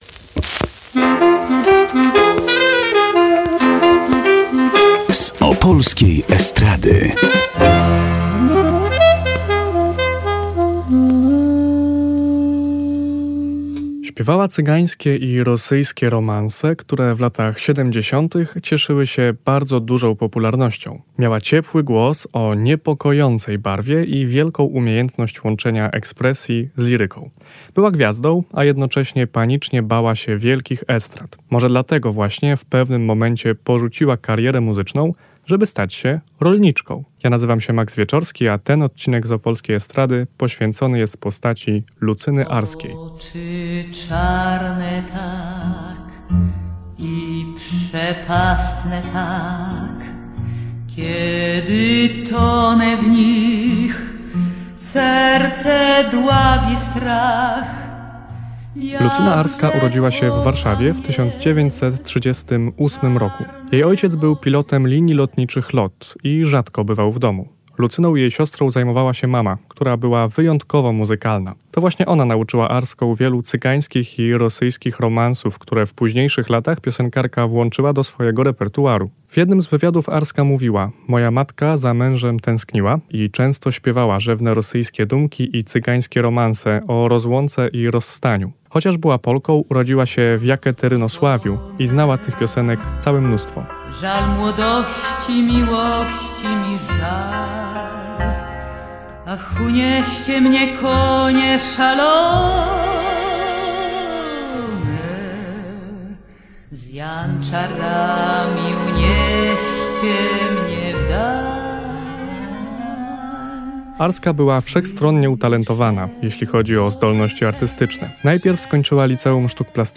Śpiewała cygańskie i rosyjskie romanse, które w latach 70-. cieszyły się bardzo dużą popularnością. Miała ciepły głos o niepokojącej barwie i wielką umiejętność łączenia ekspresji z liryką.